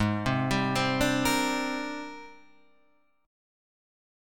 G#M#11 chord